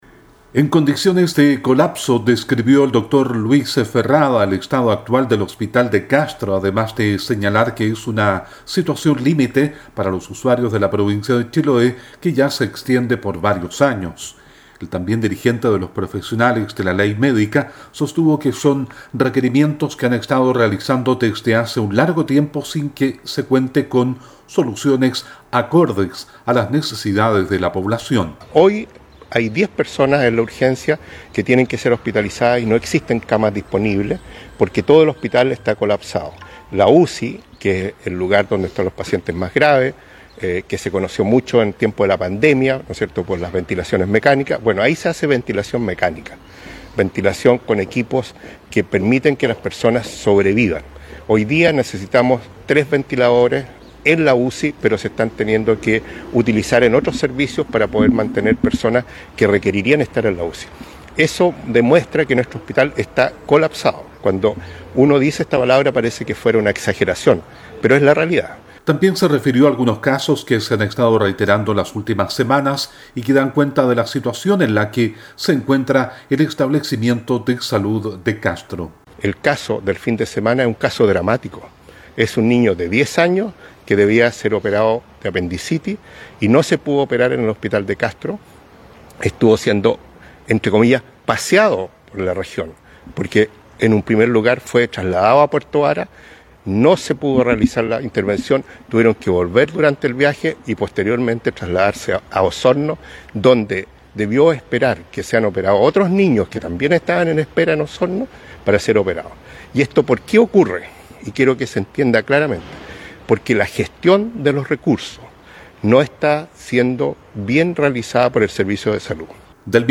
La información ahora en el despacho